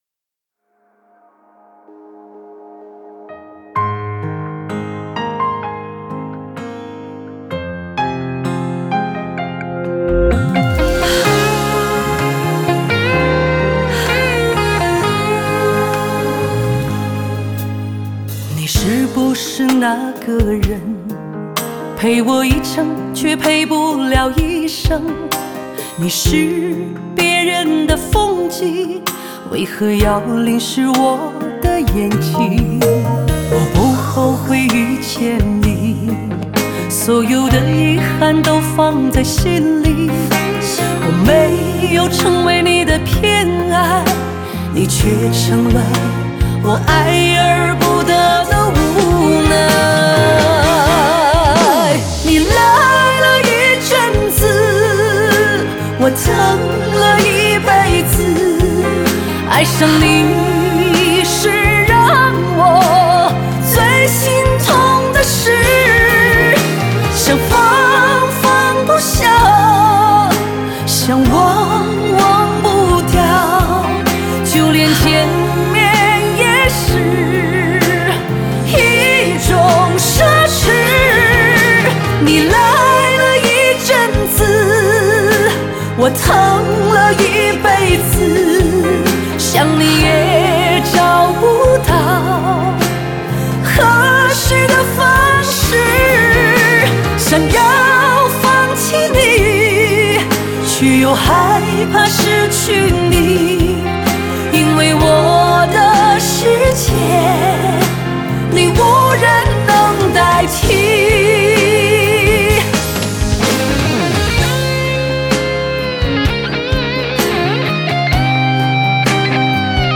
女声版